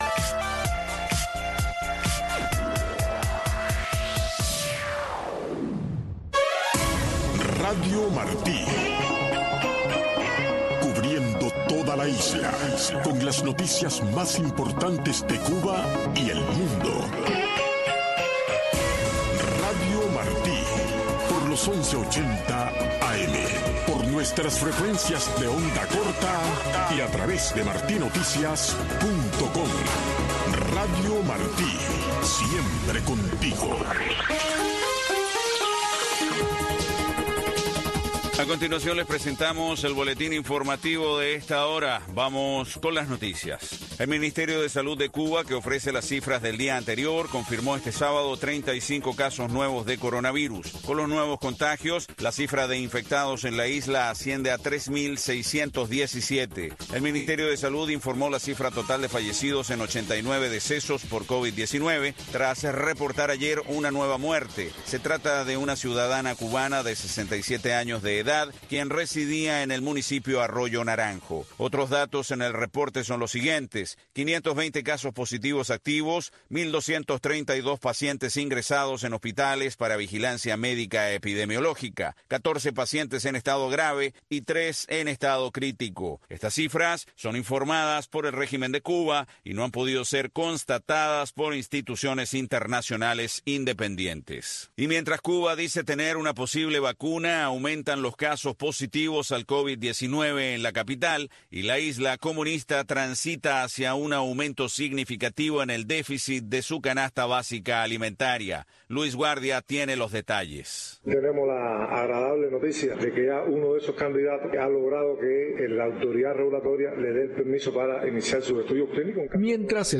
Un desfile de éxitos de la música pop internacional, un conteo regresivo con las diez canciones más importantes de la semana, un programa de una hora de duración, diseñado y producido a la medida de los jóvenes cubanos.